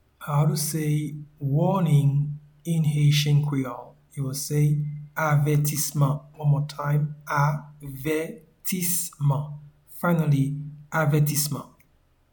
Pronunciation and Transcript:
Warning-in-Haitian-Creole-Avetisman.mp3